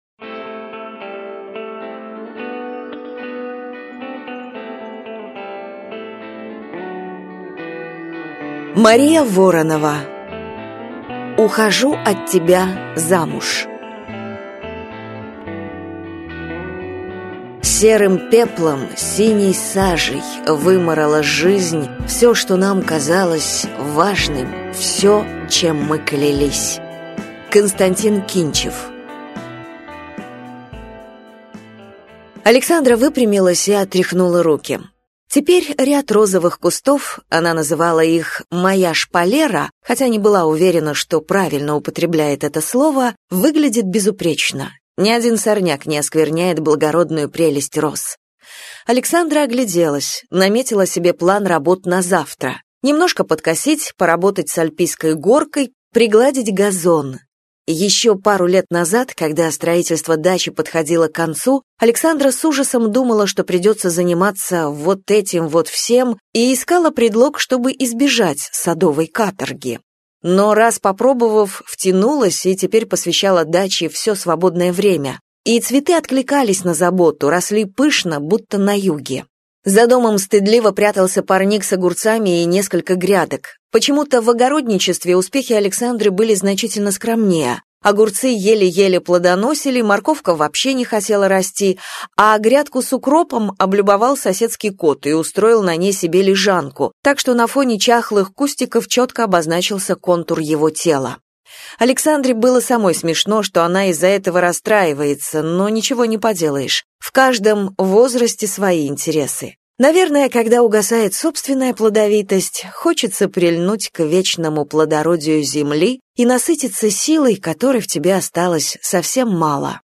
Аудиокнига Ухожу от тебя замуж | Библиотека аудиокниг